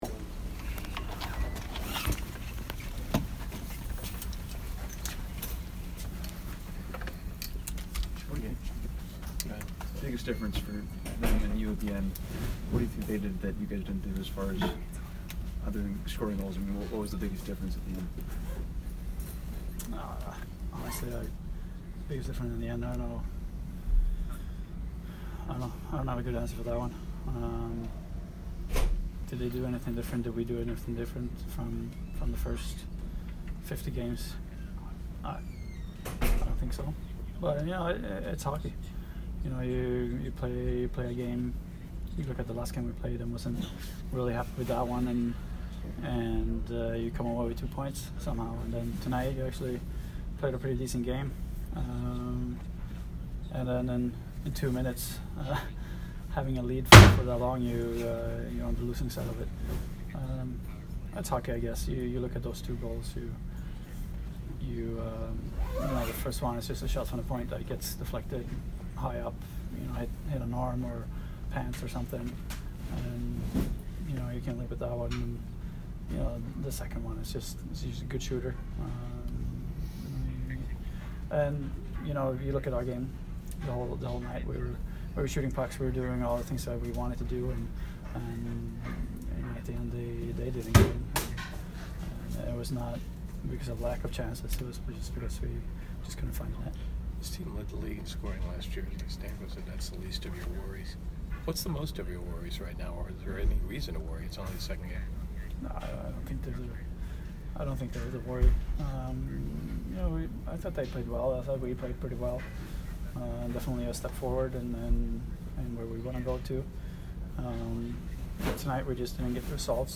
Anton Stralman post-game 10/11